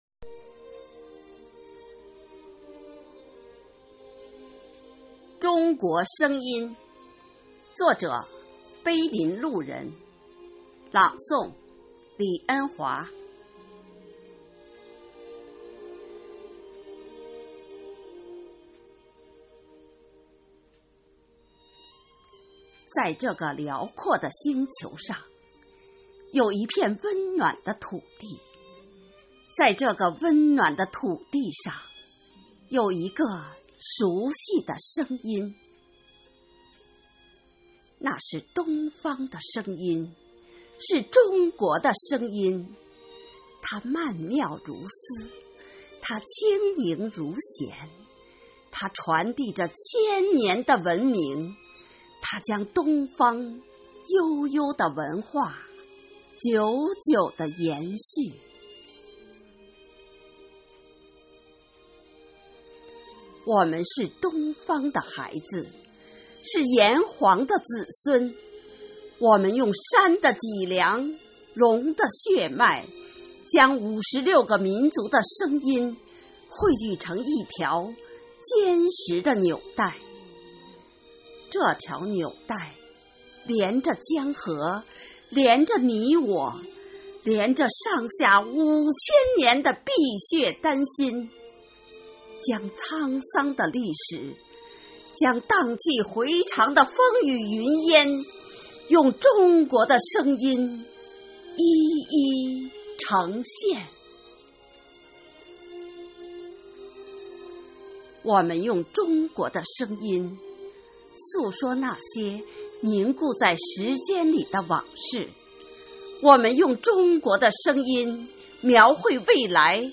“生活好课堂幸福志愿者广外科普大学朗读服务（支）队”是“生活好课堂幸福志愿者朗读服务队”的第二支队伍，简称“广外科普大学朗读支队”。“生活好课堂”的九岁生日当晚，全体队员演绎了一场激情澎湃的朗诵会。
《中国声音》独诵